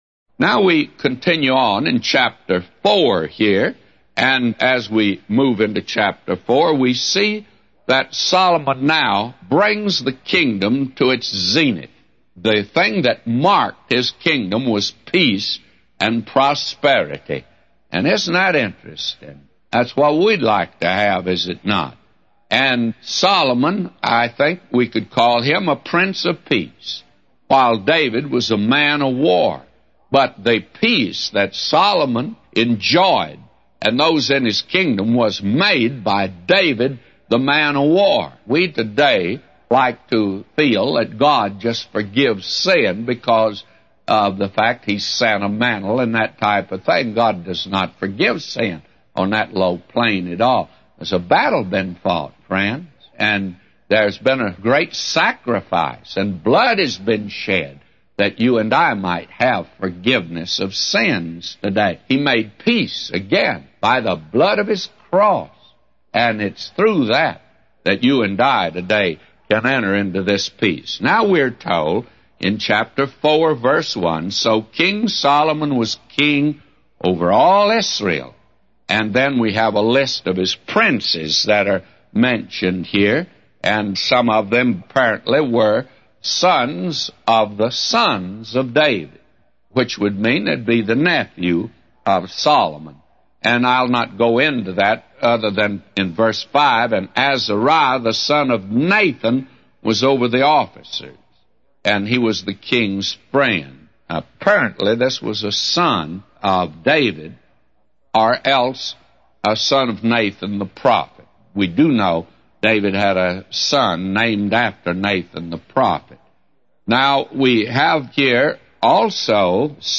A Commentary By J Vernon MCgee For 1 Kings 4:1-999